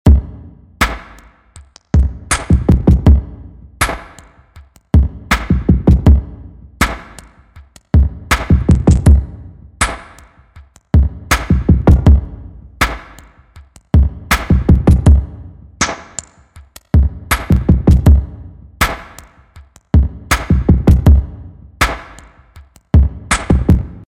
Mit geringer Diffusion, also weniger dichten Echos, verbaut der Hall nicht den Raumeindruck der anderen Instrumente.
Auch hier modifiziere ich ein Drum-Preset, fahre die Höhen etwas herunter, mache die Bässe ein wenig rund und stelle eine mittlere Kompression ein.